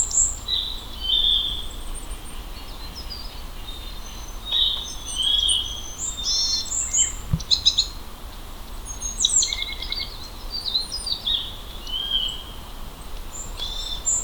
里山でタカの仲間
サシバの声（ぴっぴゅぃーー、みたいな声です） を聞くと、すでに初夏の感じがしてきます